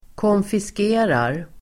Uttal: [kånfisk'e:rar]